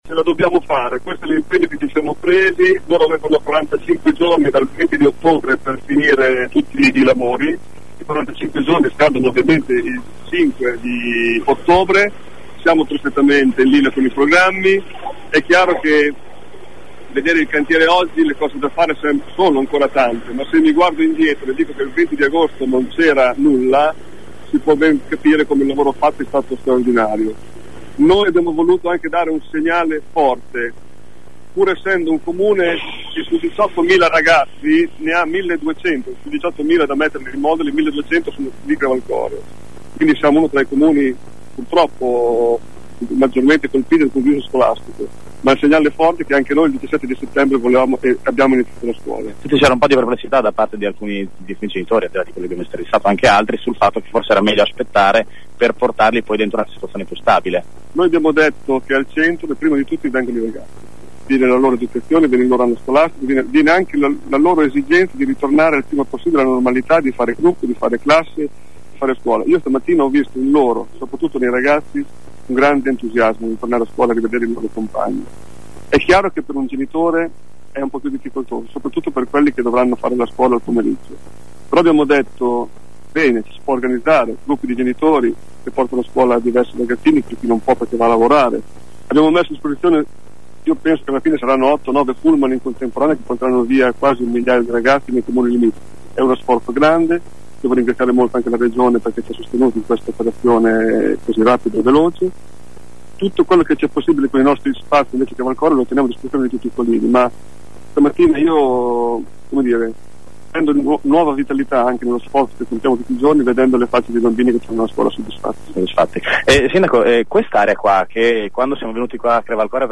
Ecco alcune delle voci raccolte questa mattina dai nostri inviati.
A Crevalcore l’impegno è di portare tutti a scuola nel prefabbricato entro il 10 ottobre. Ascolta il sindaco Claudio Broglia